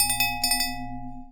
chime_bell_08.wav